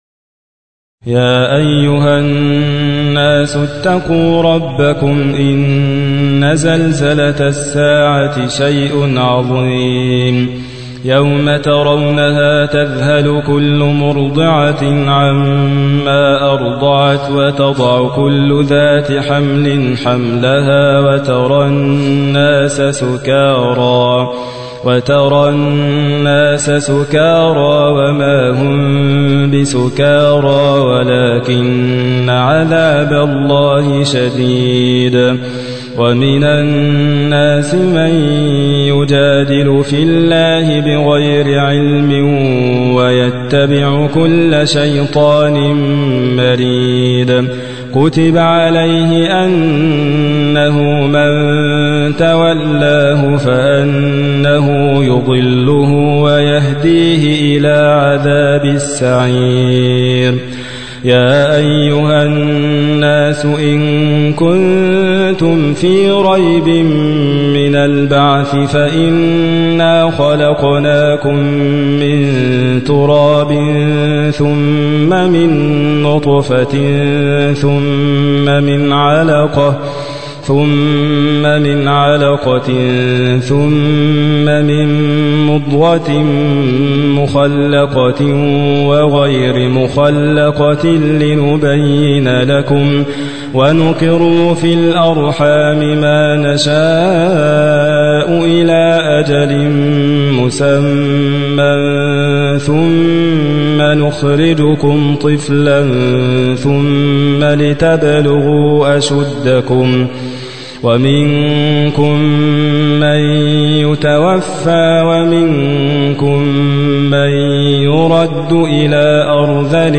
موقع يا حسين : القرآن الكريم 22.